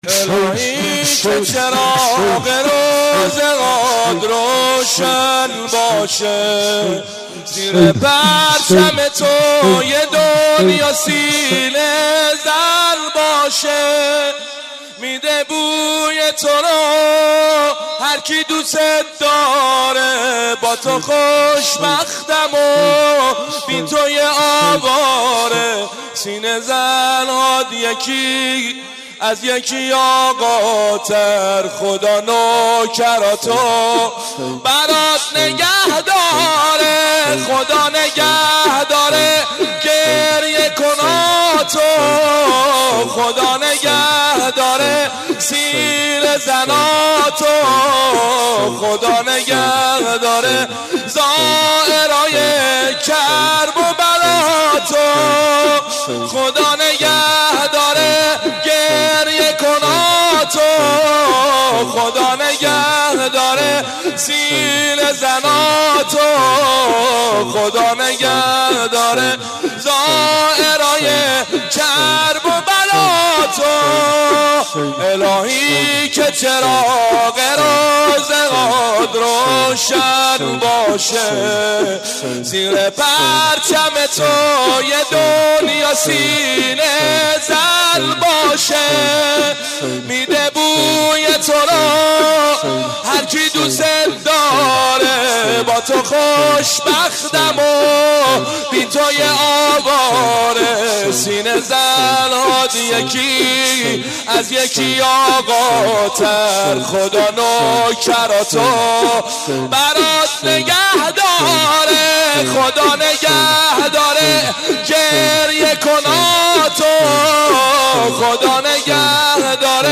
عقیق: در این مراسم تعدادی از ذاکرین اهل بیت(ع) مداحی کردند که در ادامه صوت مداحی ها منتشر می شود:
مداحی
در مراسم ترحیم